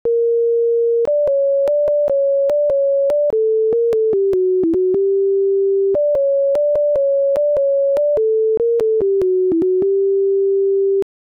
To demonstrate how the metrics can be used to compare tunes, we use as our example a dancing tune from the 17th century called Devil’s Dream, found in the 1657 and 1670 versions of the Dancing Master, compiled by John Playford.